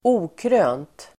Ladda ner uttalet
Uttal: [²'o:krö:nt]